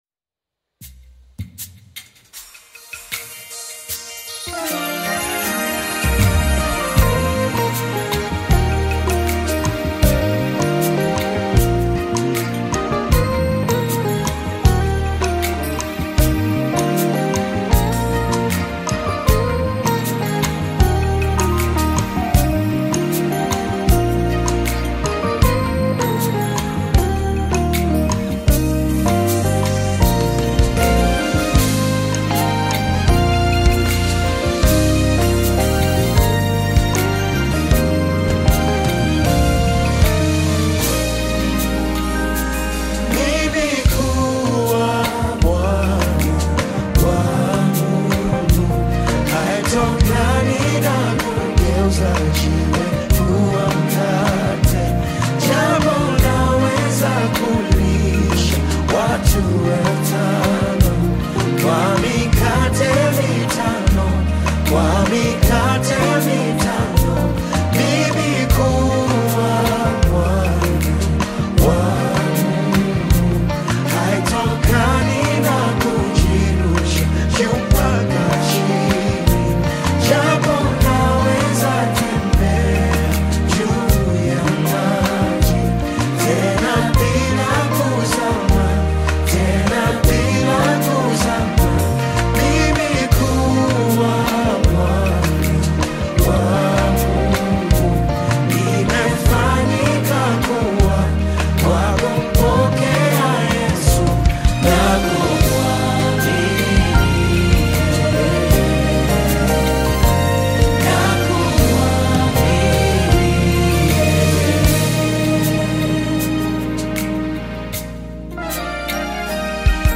Nyimbo za Dini Worship music
Worship Gospel music track